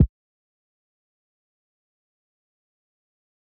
MD Kicks (3).wav